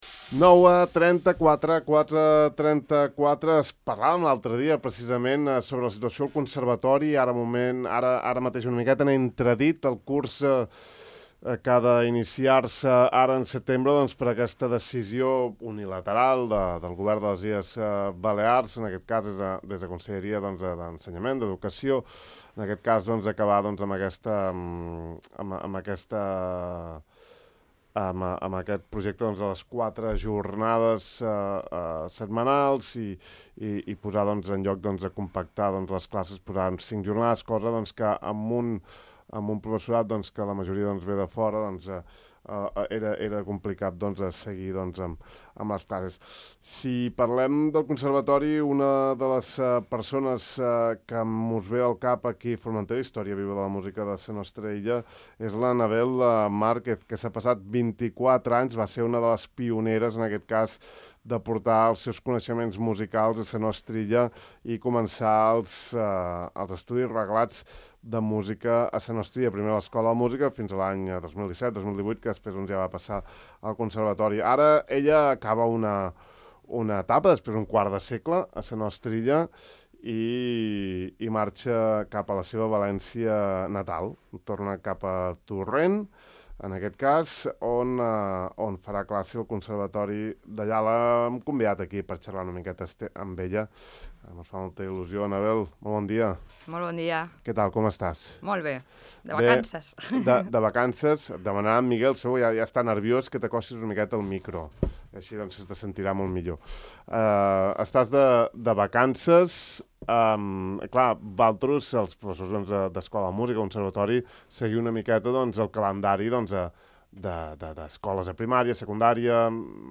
Avui hem xerrat amb ella en una conversa per saber més de la seva trajectòria aquí i de la seva decisió de marxar.